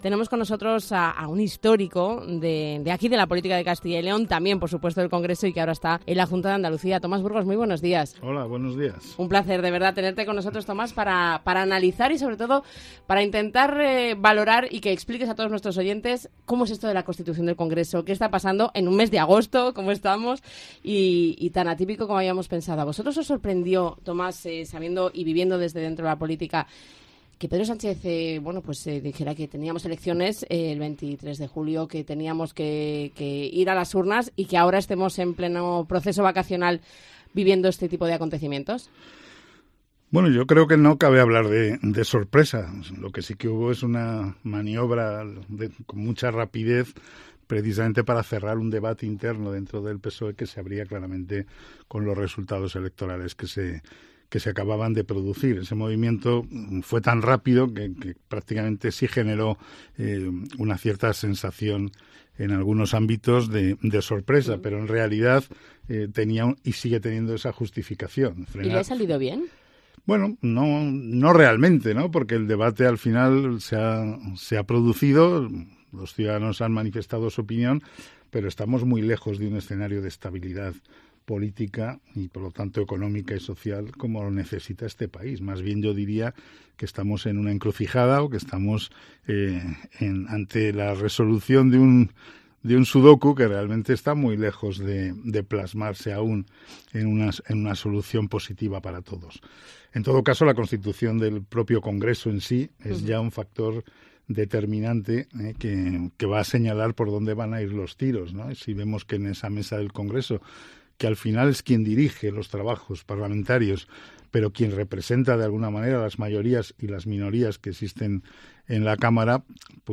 XV Legislatura. Análisis con: Tomás Burgos y Álvaro Morales